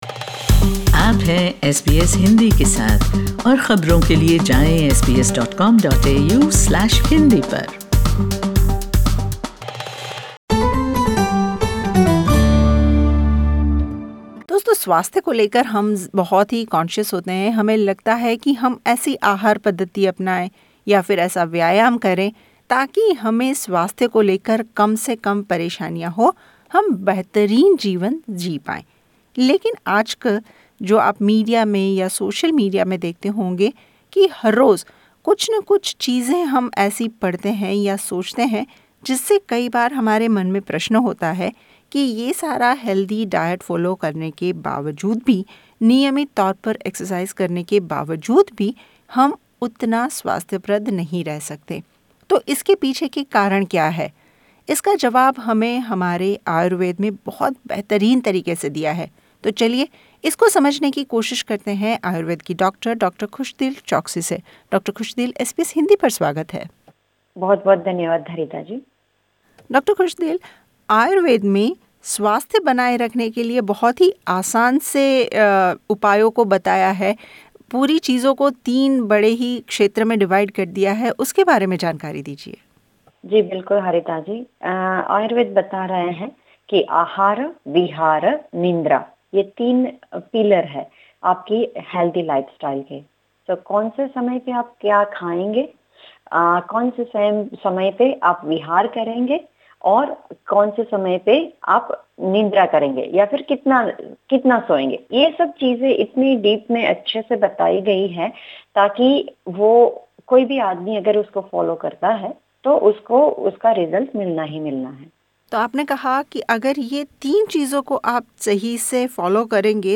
इस बातचीत में उन्होंने स्वाथ्य को बनाये रखने के लिए ३ मुख्य पैरामीटर के बारे में भी बताया